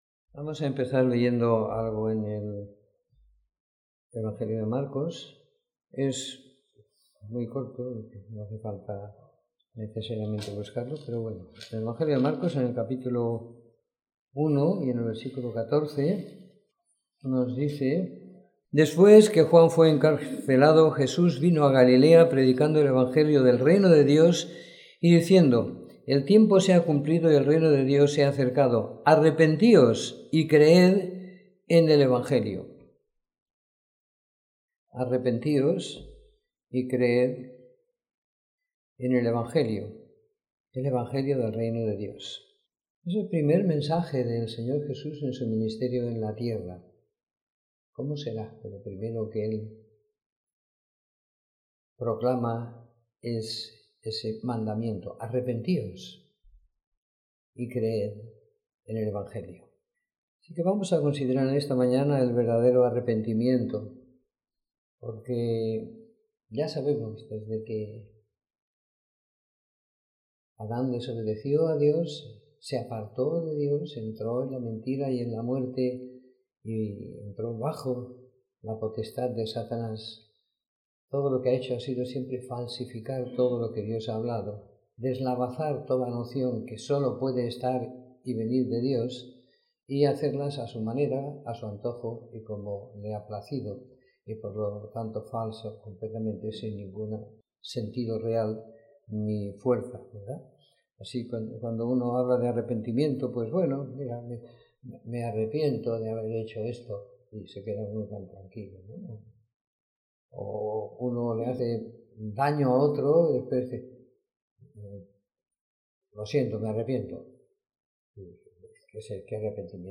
Reunión semanal de Predicación del Evangelio.